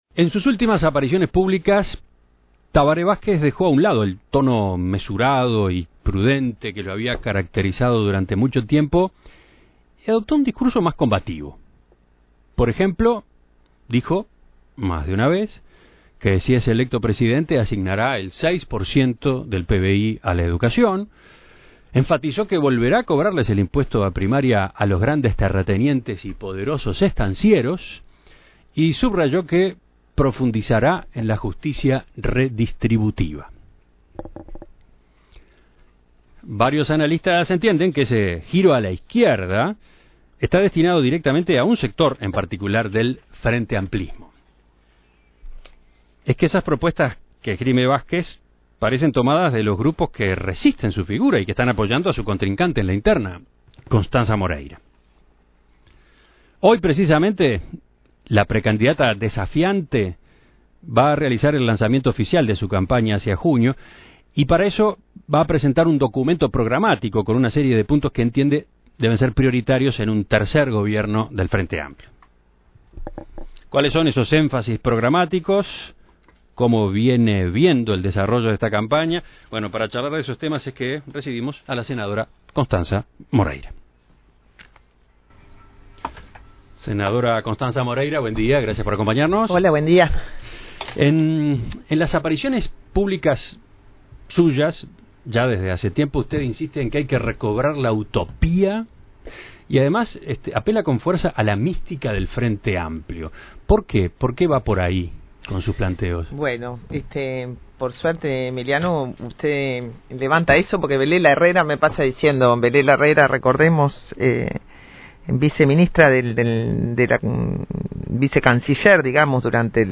En Perspectiva entrevistó a la senadora Constanza Moreira sobre el desarrollo de su campaña de cara al 1ero de Junio.